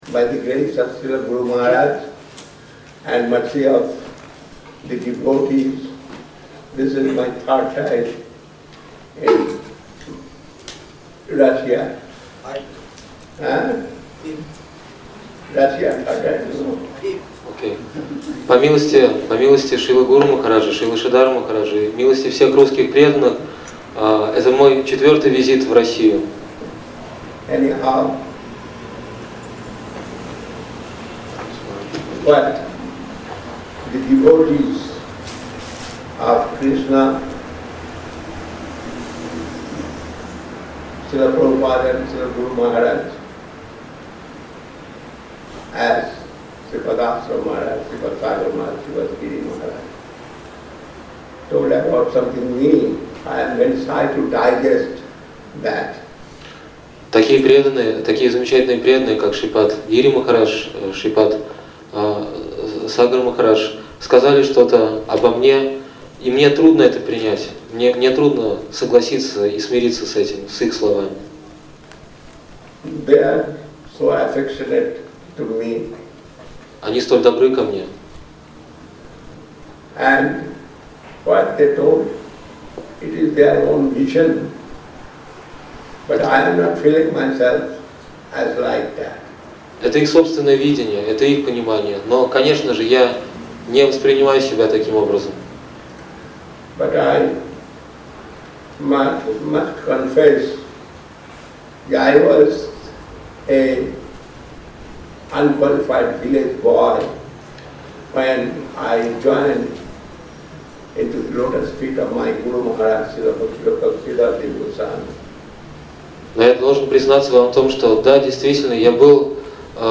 Торжественная речь Часть 2 Введение в сознание Кришны
Место: Культурный центр «Шри Чайтанья Сарасвати» Москва